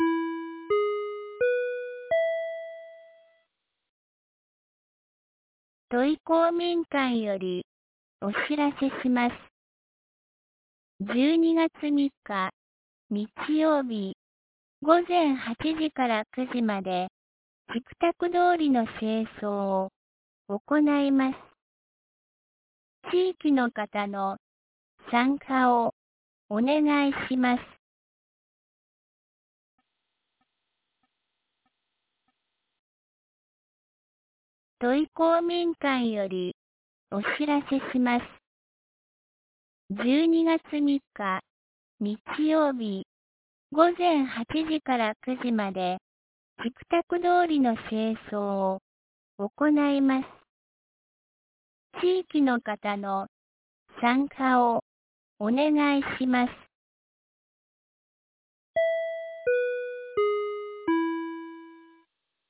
2023年12月02日 17時11分に、安芸市より土居、僧津へ放送がありました。